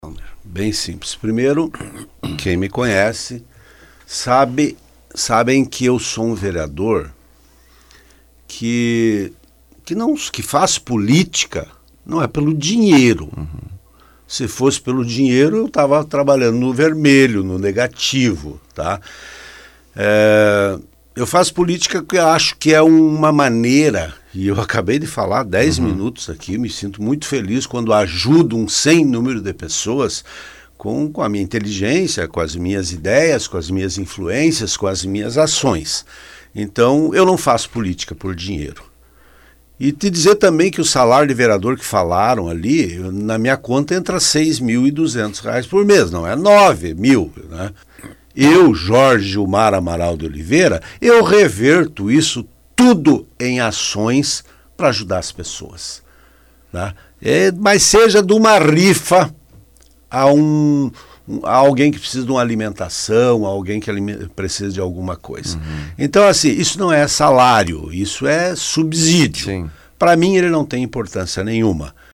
Vereador Jorge Amaral, entrevistado no Fatorama sobre assuntos corriqueiros de um agente público, disse que saúde é um tema difícil de tratar, referindo-se ao impasse envolvendo paciente internada na UPA quando deveria estar no Hospital de